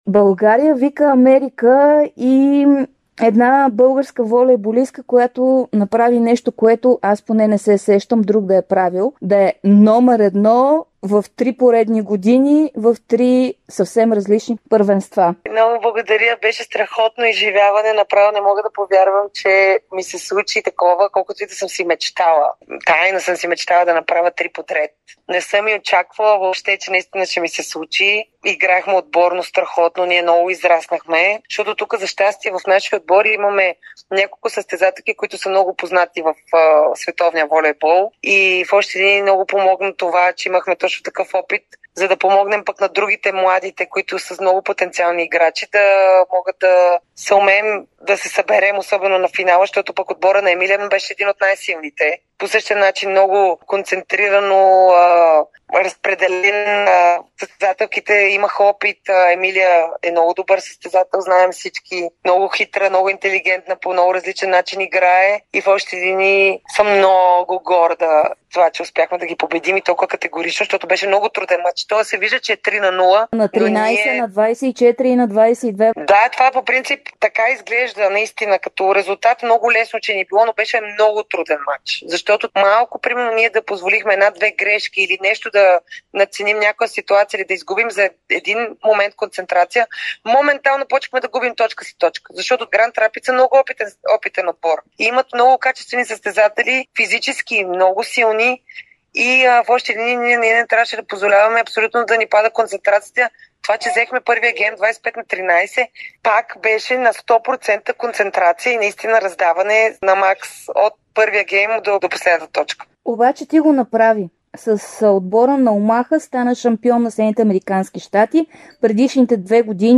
ексклузивно интервю